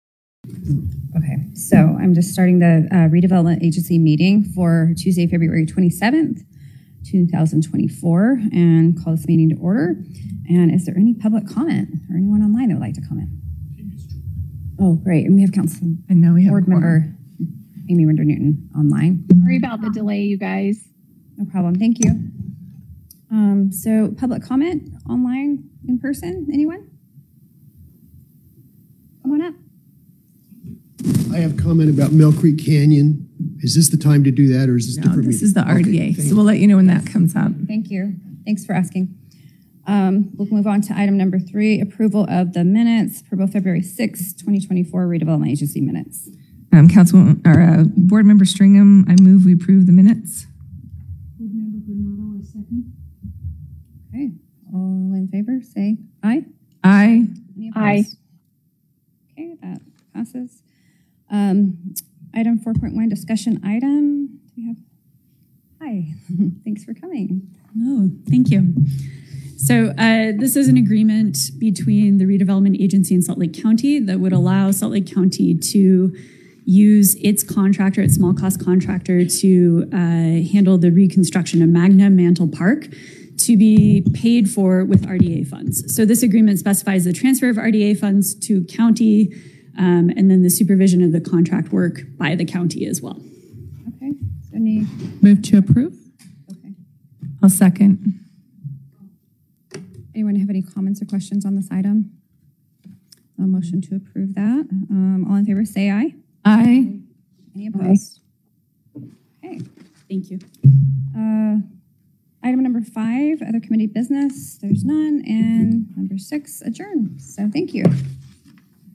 Meeting
Tuesday, February 27, 2024 12:50 PM Council Chambers, N1-110 Upon request and with three working days' notice, Salt Lake County will provide free auxiliary aids and services to qualified individuals (including sign language interpreters, alternative formats, etc.).